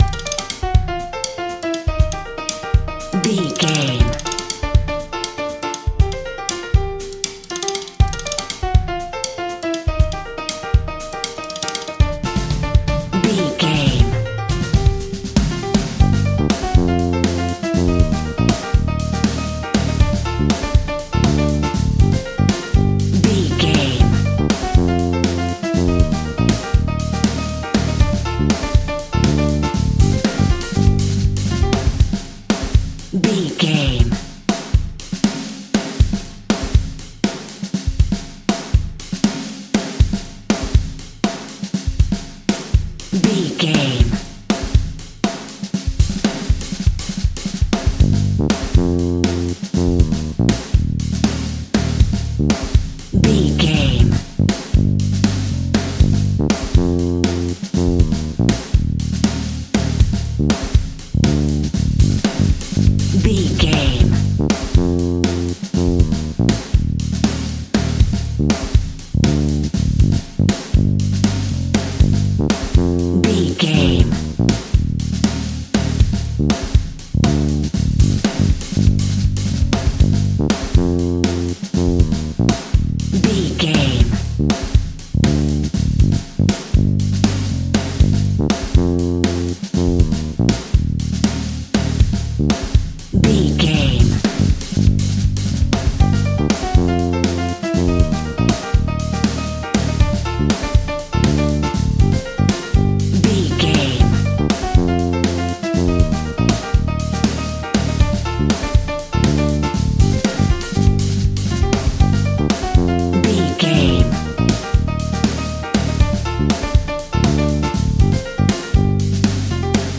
Aeolian/Minor
cuban music
uptempo
frisky
bass guitar
brass
saxophone
trumpet
fender rhodes
clavinet